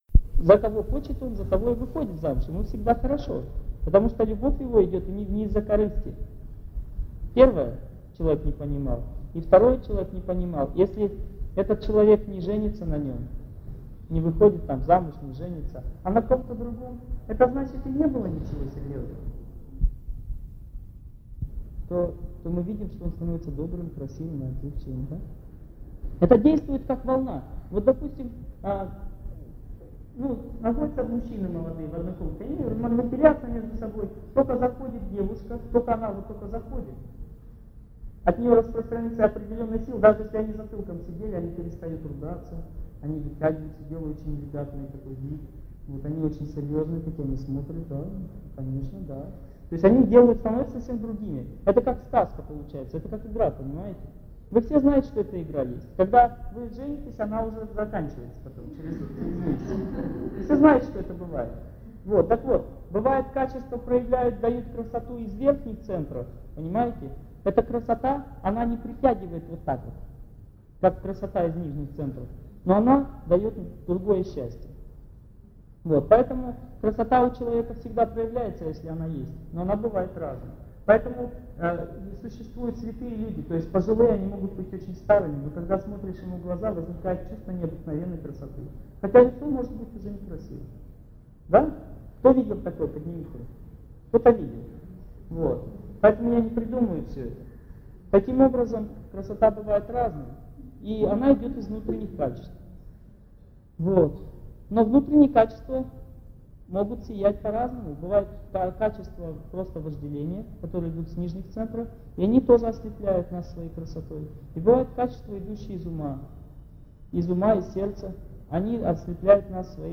Аудиокнига Семья и дети | Библиотека аудиокниг